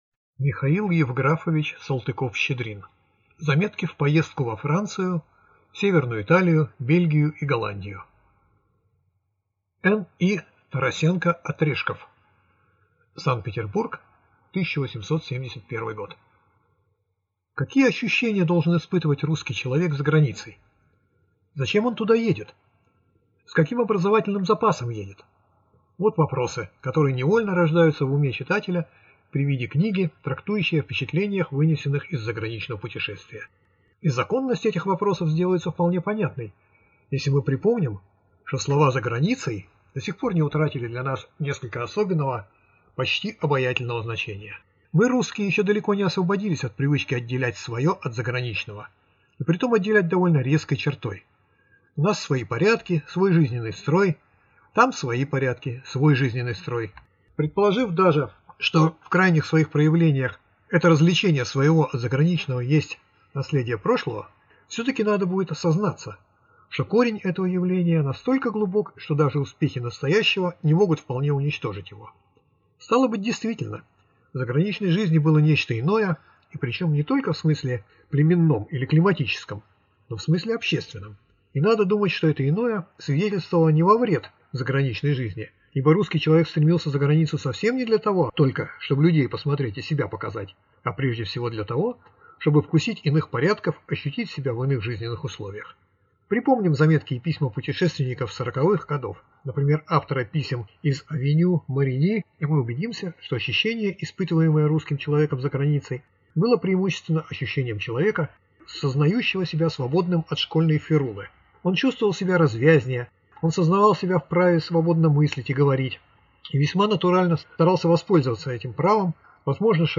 Аудиокнига Заметки в поездку во Францию, С. Италию, Бельгию и Голландию.